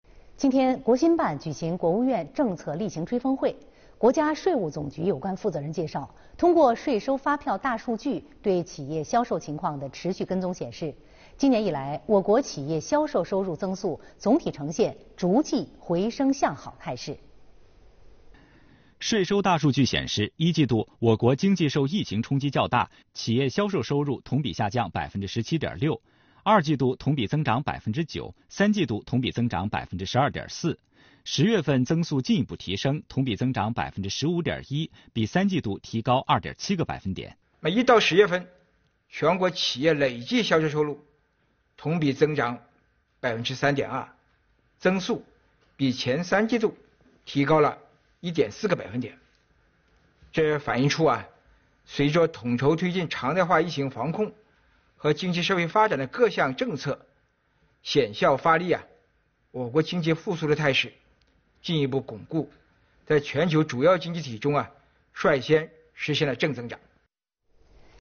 11月12日，国务院新闻办举办国务院政策例行吹风会，介绍财政资金直达机制和减税降费工作进展及取得成效。财政部副部长许宏才和有关司局负责同志、国家税务总局收入规划核算司司长蔡自力参加并回答记者提问。